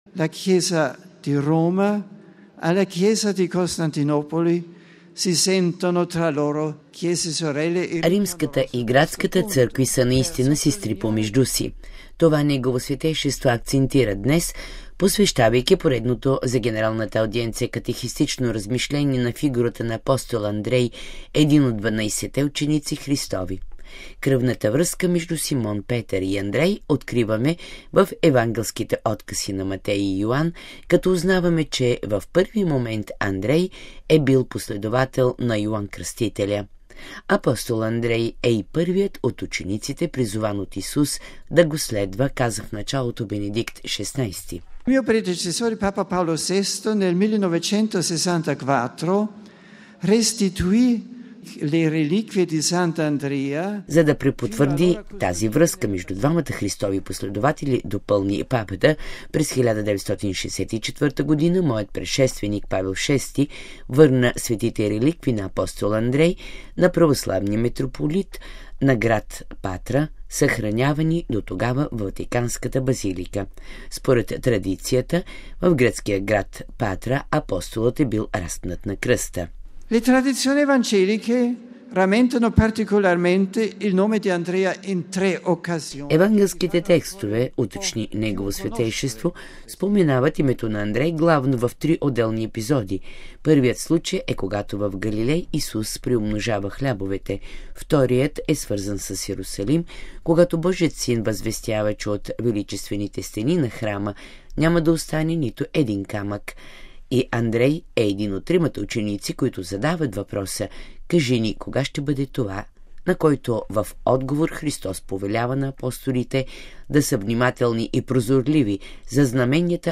Това подчерта Бенедикт ХVІ на генералната аудиенция. Тя се проведе на площад Св.Петър в присъствието на повече от 30 хил. верни и поклонници от цял свят, а в края Папата отправи призива да участват в процесията на празника Тяло и Кръв Христови, която на 15 юни ще премине прец централните римски улици.